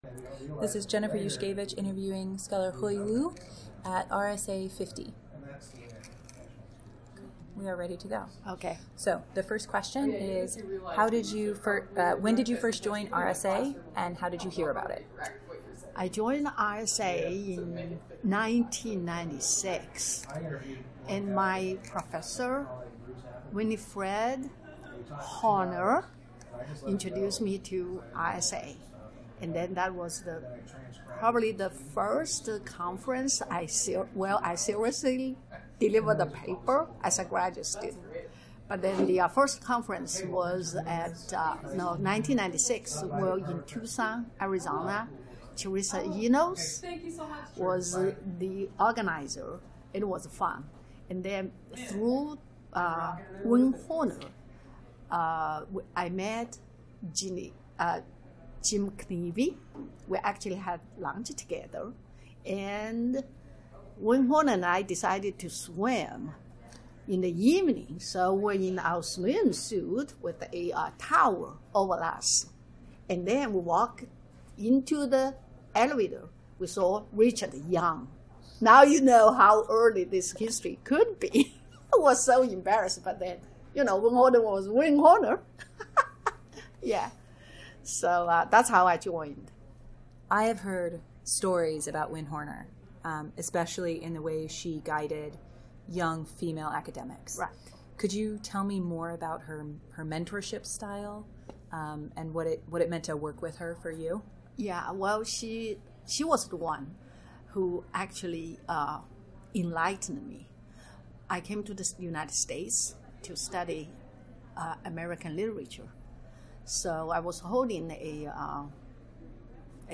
Oral history interview
2018 RSA Conference in Minneapolis, Minnesota